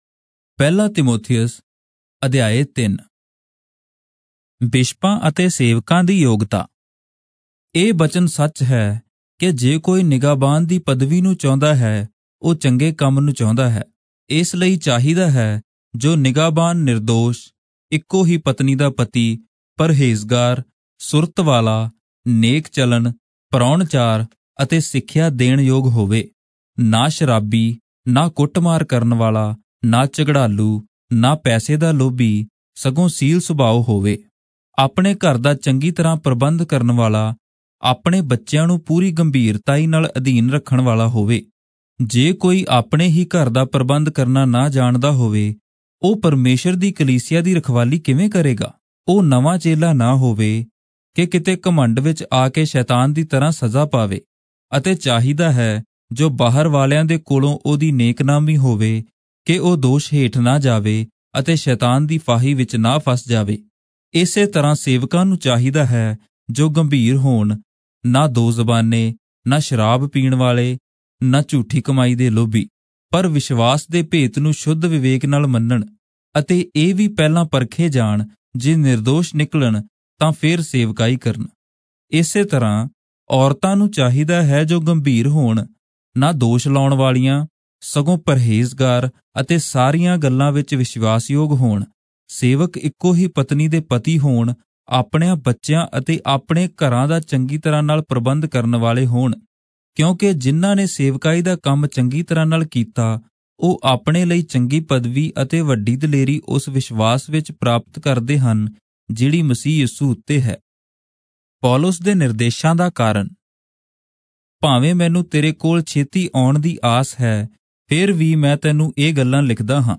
Punjabi Audio Bible - 1-Timothy 2 in Irvpa bible version